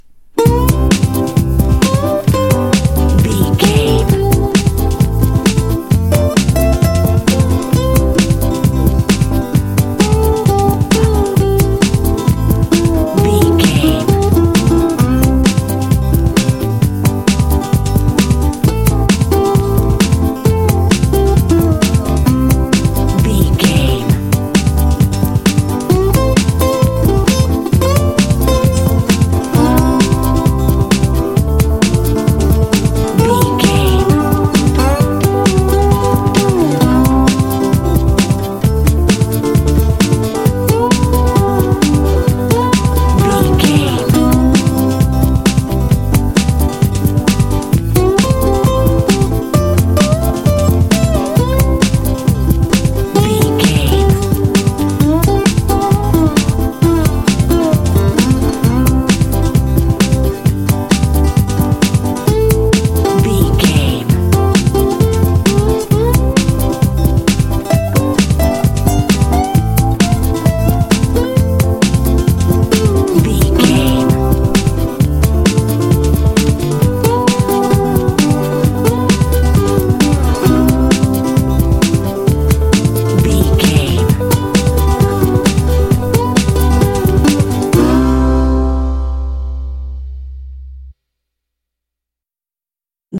Ionian/Major
happy
fun
acoustic guitar
drums
banjo
bass guitar
playful